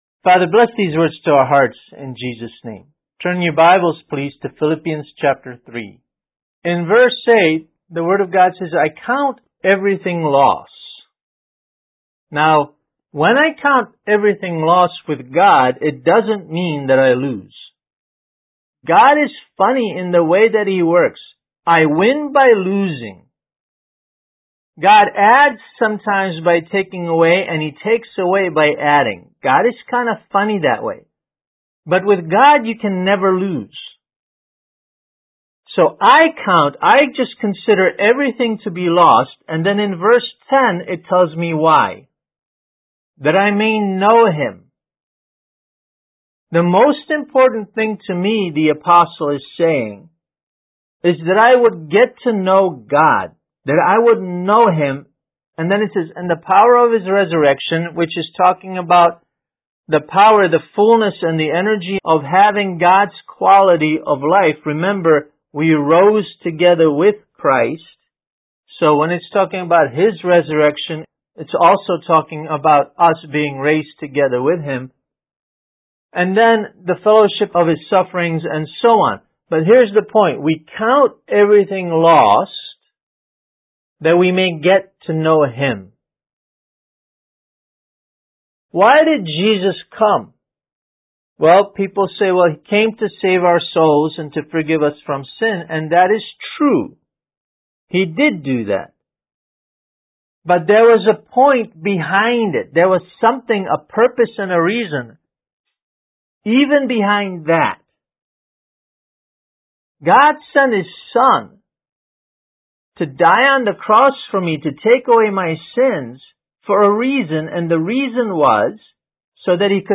Kids Message: God Wants Your Friendship